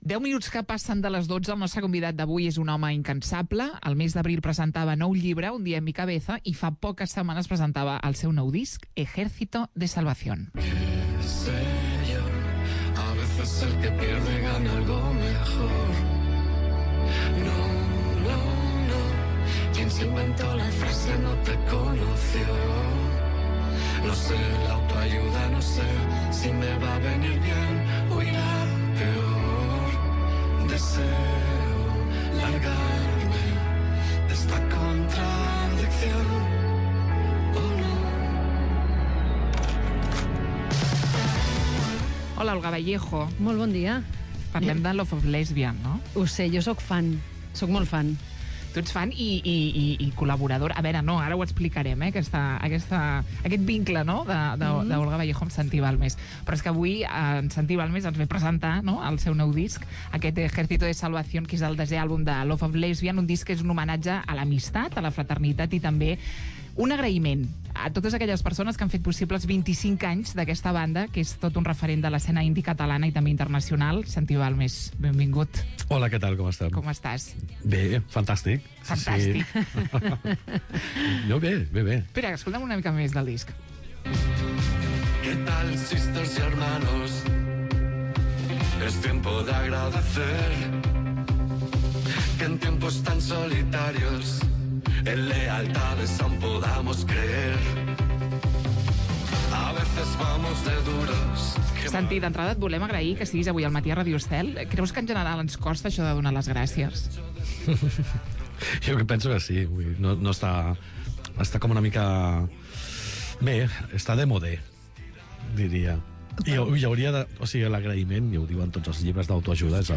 Escolta l'entrevista a Santi Balmes, líder de 'Love of Lesbian'